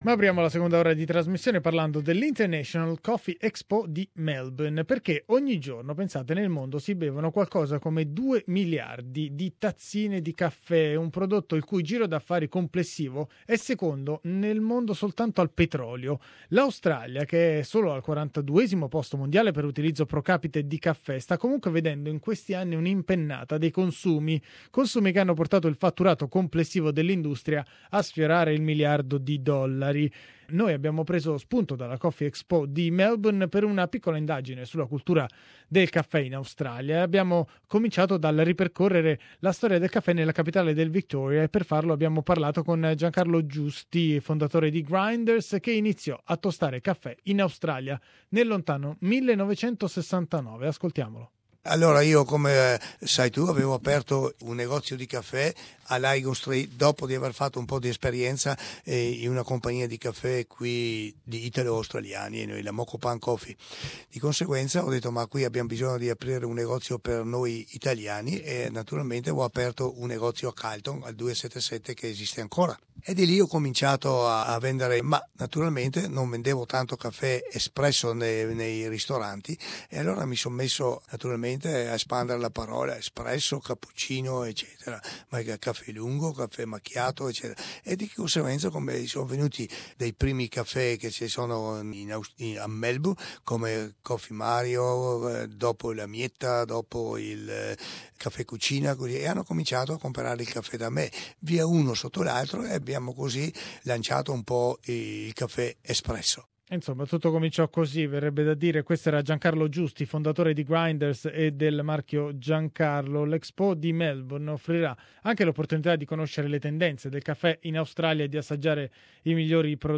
But where does Australia stand in this 20 billion dollars industry? We talked about the past, the present and the future of the coffee in Australia with a panel of experts.